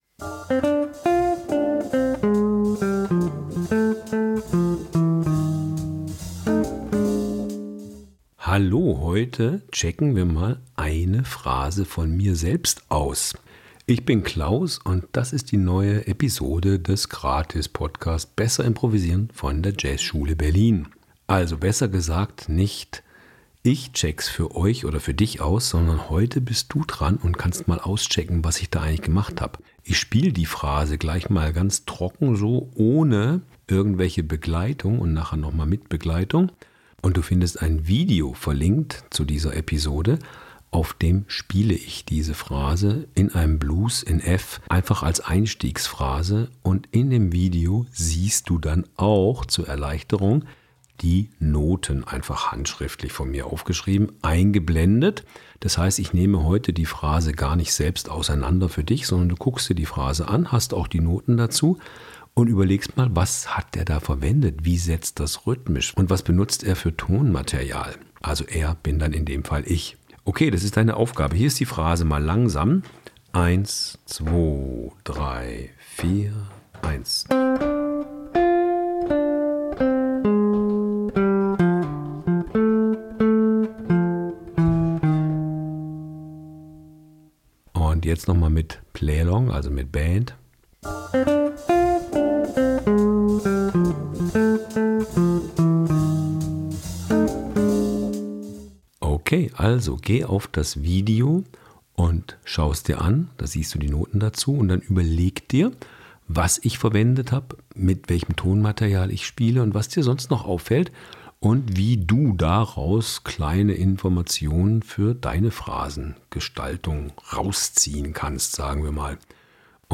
Eine Gitarren-Phrase
über einen Blues in F
Dieses Mal gibt es einen Schnipsel Live Musik inklusive